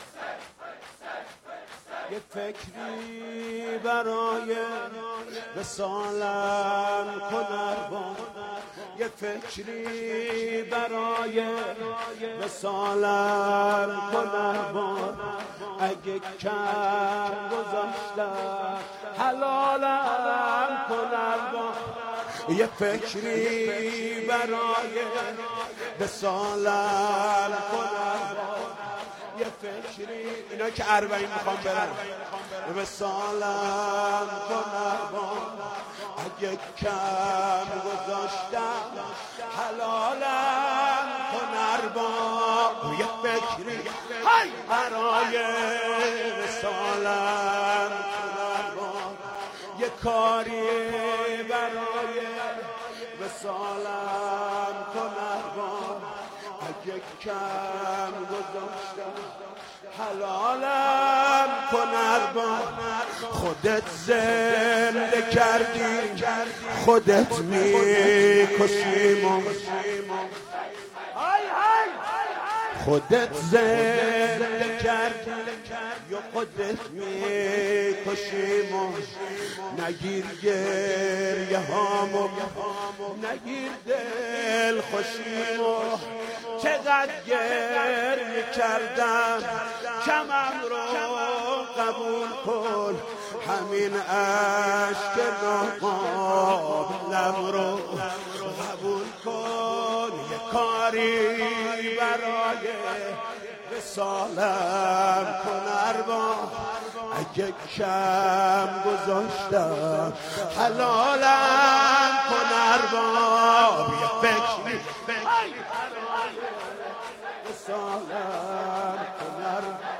shoor_karimi_5_safar_96.mp3